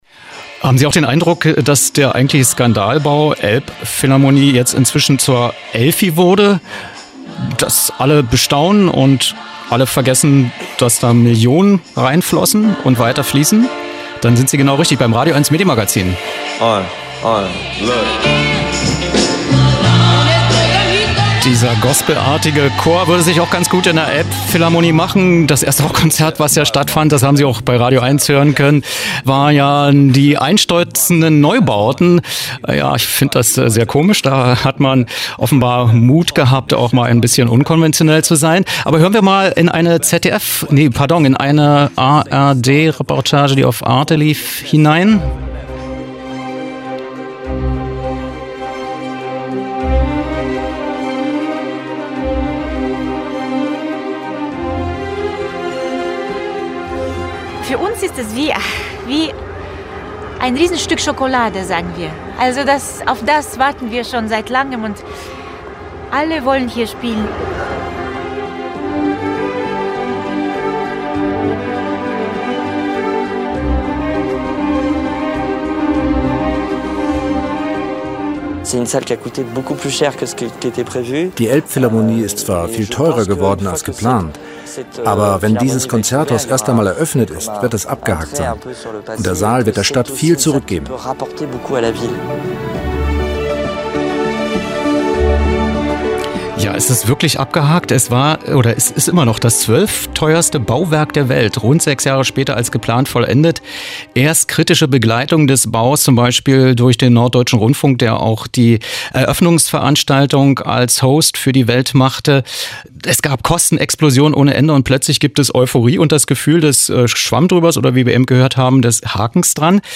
Telefon-Interview zur medialen Begleitung der Elbphilharmonie durch den NDR